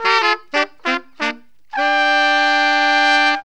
HORN RIFF 27.wav